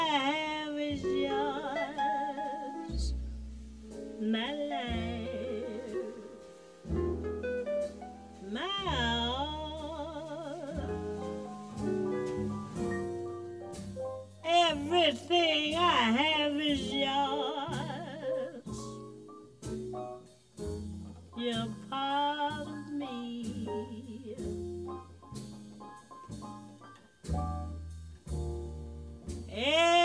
Thats about the best you can get with the built-in effects:
It is not cleaned up (a lot of artefacts are remaining).